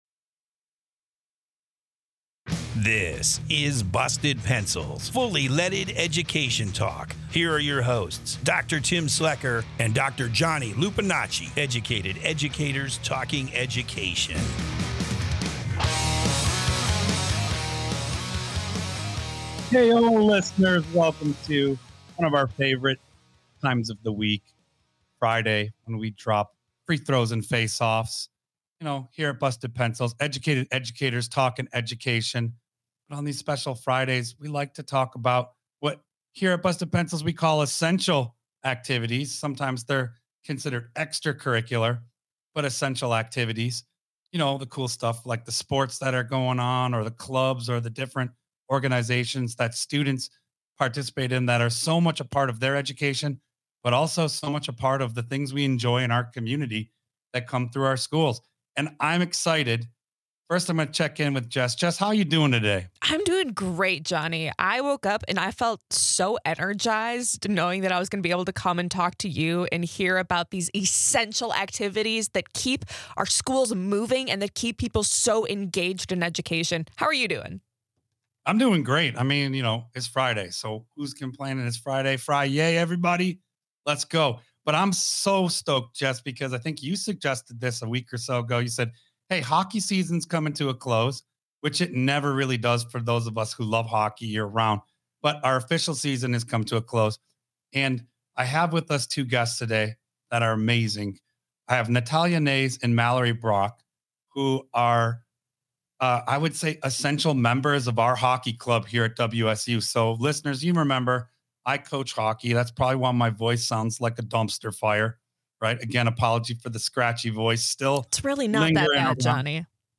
Today, we hear from two young women who support a sport team.